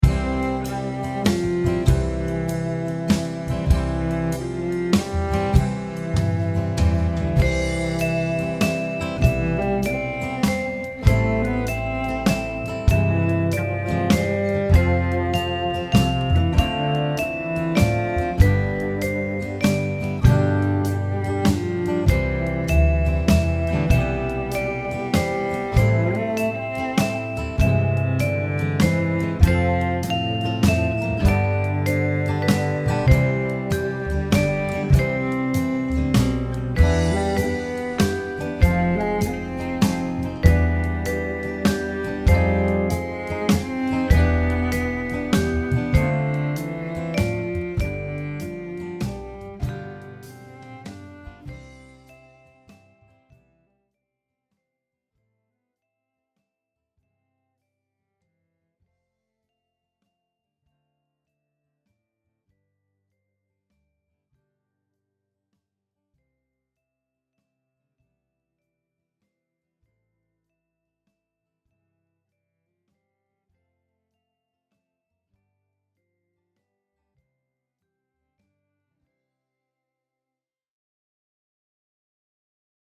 Maza dziesmiņa Play-along.
Spied šeit, lai paklausītos Demo ar melodiju